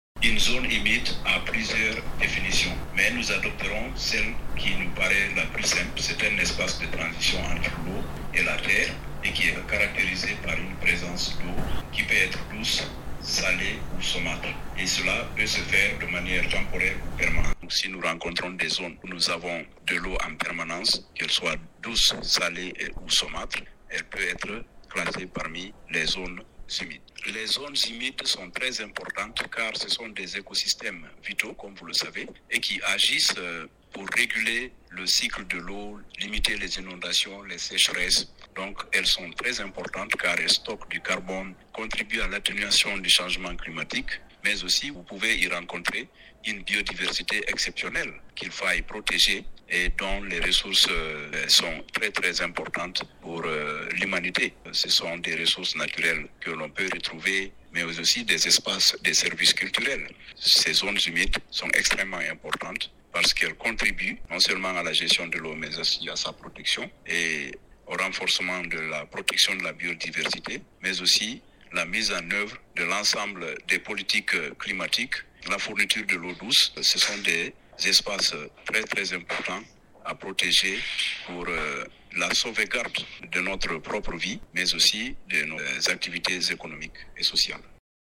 expert environnementaliste